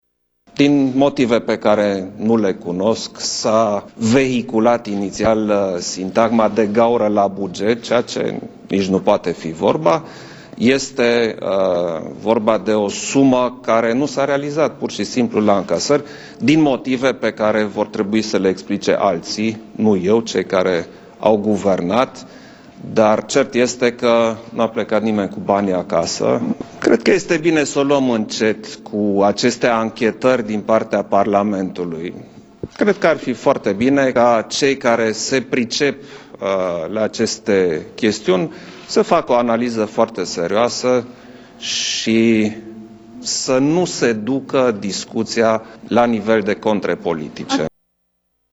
În ceea ce privește acuzația lansată de liderul PSD, Liviu Dragnea, potrivit căruia din buget ar lipsi 10 miliarde de lei, președintele Klaus Iohannis a precizat că este vorba despre o sumă care efectiv nu s-a realizat la încasări, iar explicațiile nu ar trebui să vină de la președenție: